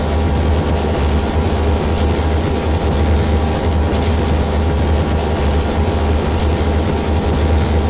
laser-beam.ogg